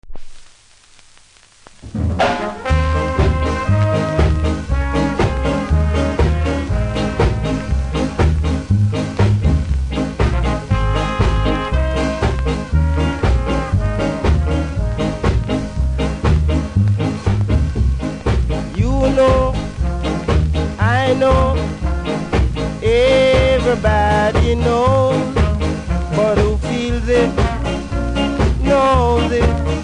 両面プレスノイズあり。
両面キズ多数で見た目悪いですがノイズは見た目ほど酷くありませんので多少のノイズ大丈夫な人ならプレイ可レベル。